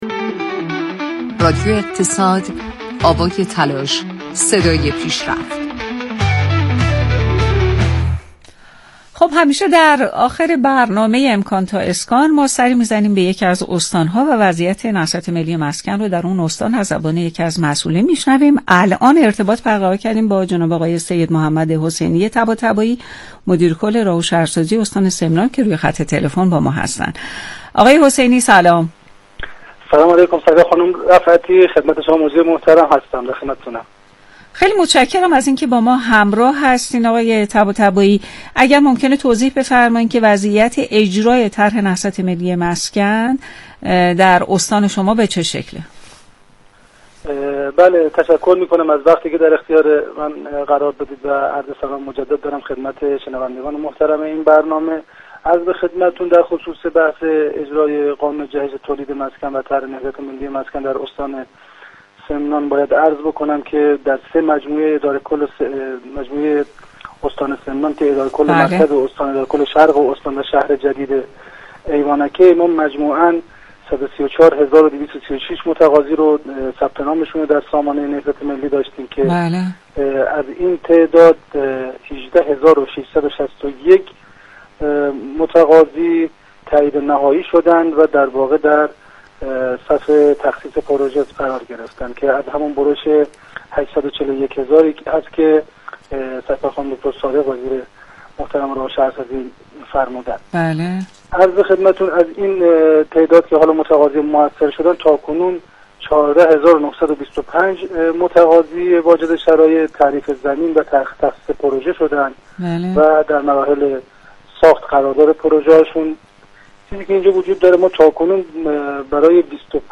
مدیرکل راه و شهرسازی استان سمنان در گفتگوی زنده تلفنی با رادیو «اقتصاد» آخرین روند اجرای طرح نهضت ملی مسکن در استان را تشریح کرد.